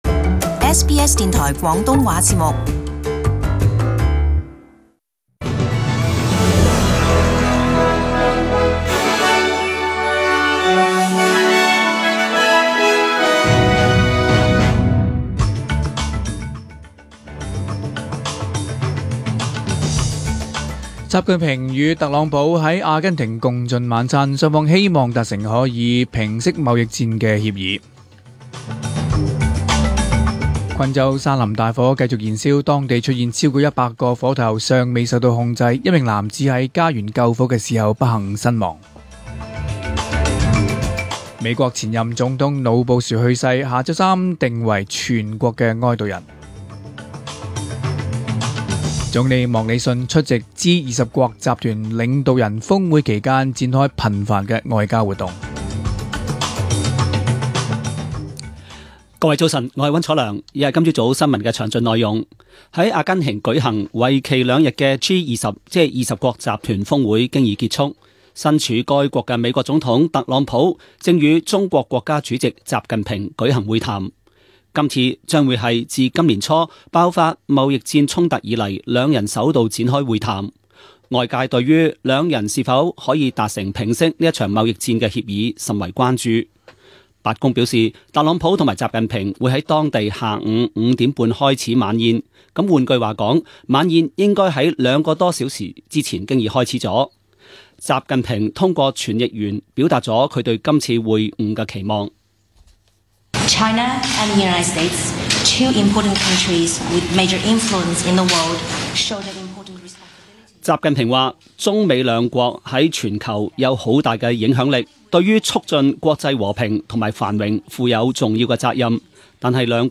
2018年12月2日广东话节目10点钟新闻